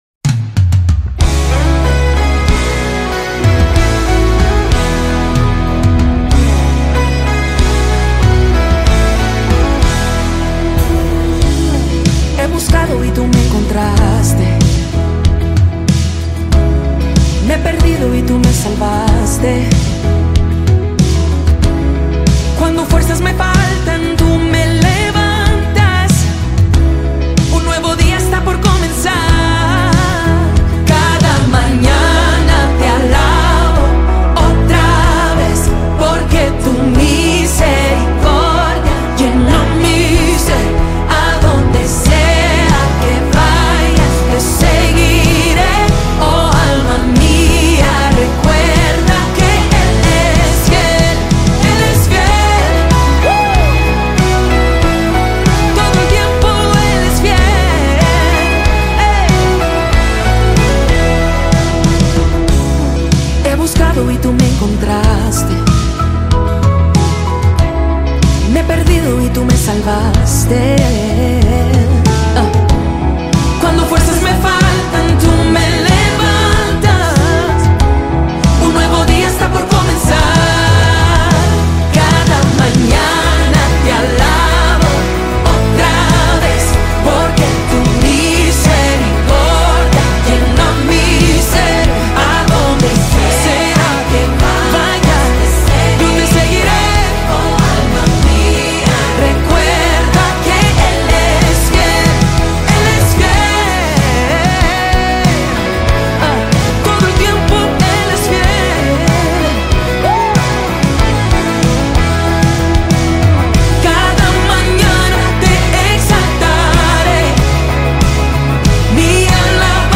Jesus Worship